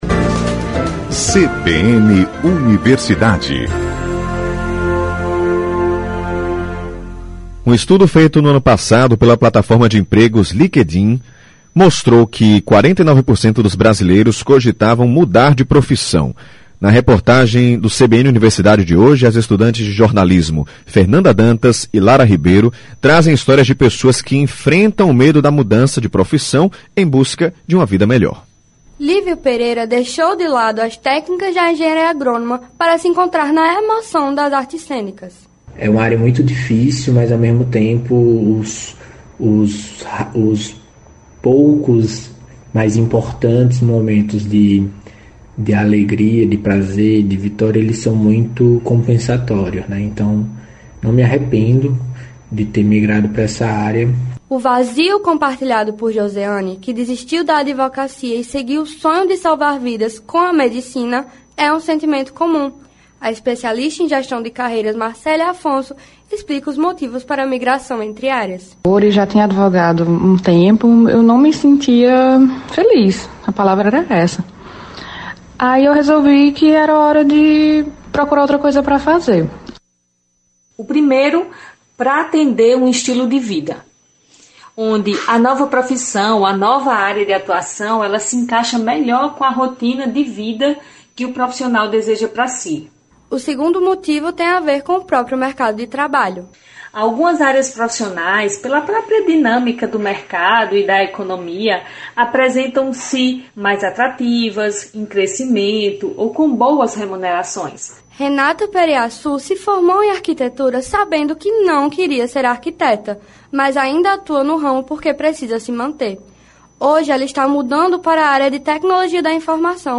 Por Alunos de Jornalismo da UFPB